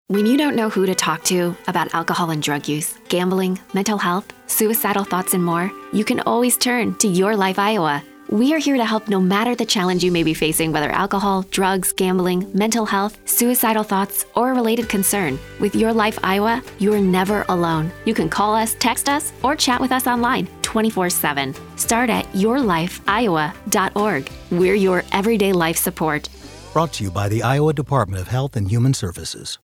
:30 Radio Spot | YLI Awareness (Female-4)